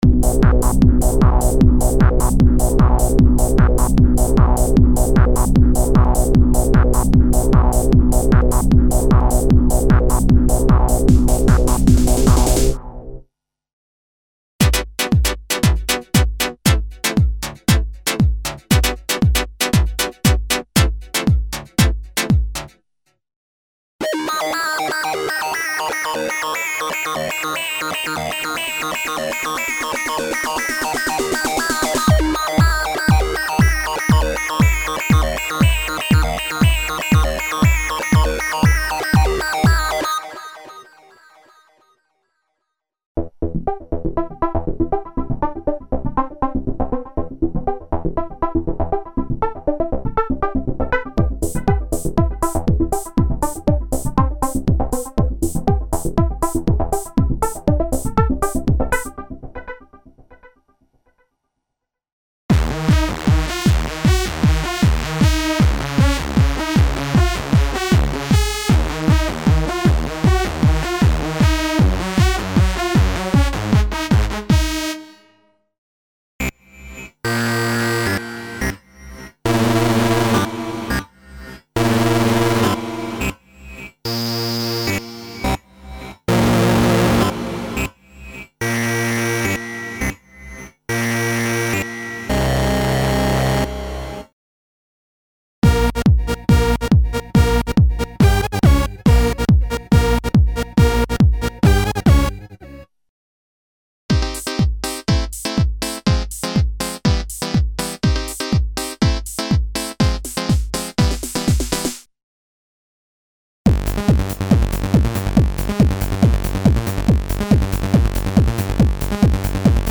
Original collection of modern stacks, synth chords, filtered tone intervals and sound banks specially designed for Jungle, Trance and Techno music styles.
Info: All original K:Works sound programs use internal Kurzweil K2600 ROM samples exclusively, there are no external samples used.